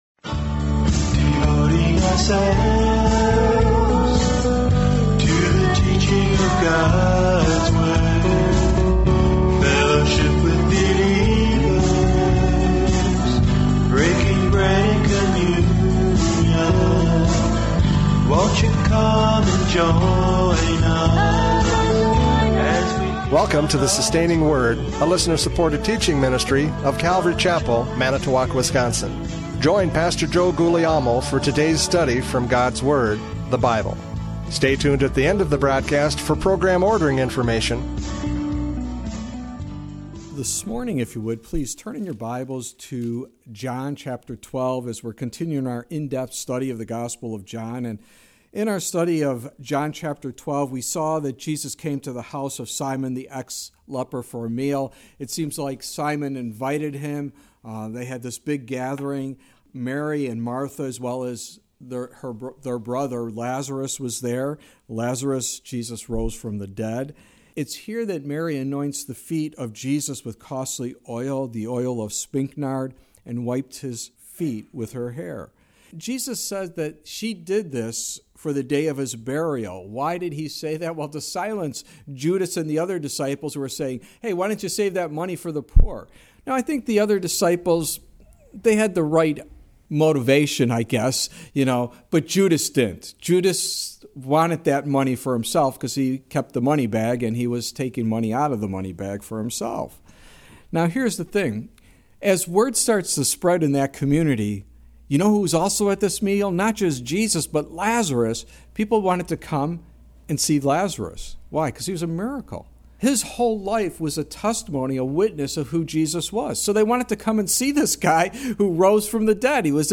John 12:12-22 Service Type: Radio Programs « John 12:9-11 Destroying the Evidence!